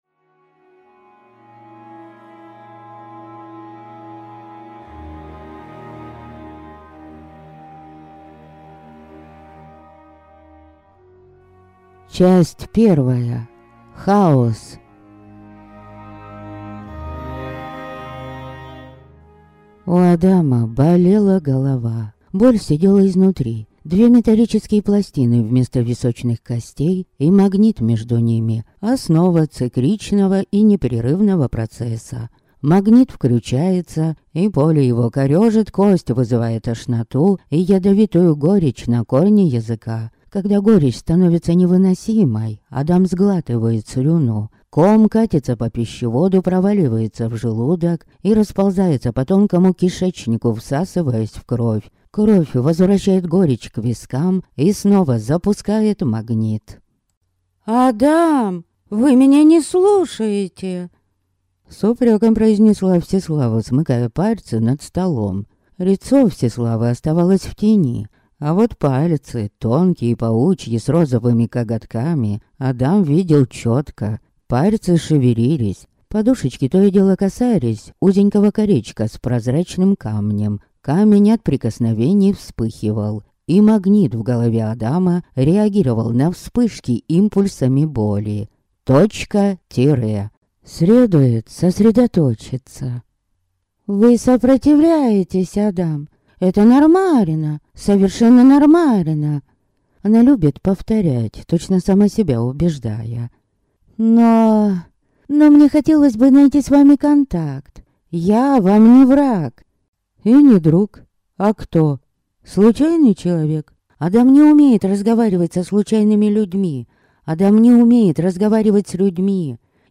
Аудиокнига Фотограф смерти | Библиотека аудиокниг
Прослушать и бесплатно скачать фрагмент аудиокниги